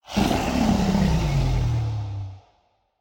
mob / enderdragon / growl1.ogg
growl1.ogg